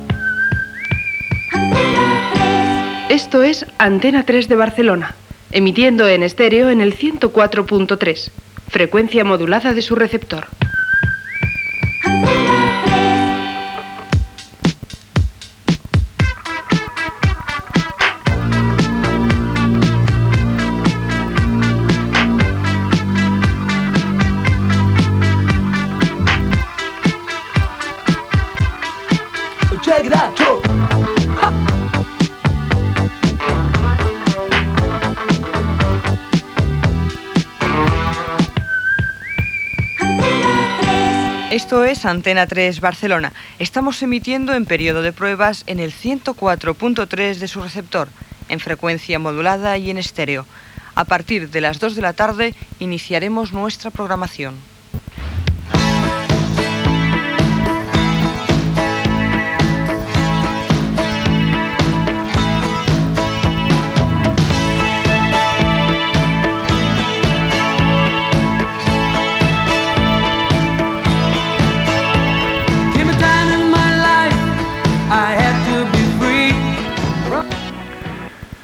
Identificació en període de proves el dia de la inauguració de les emissions regulars a Barcelona.
FM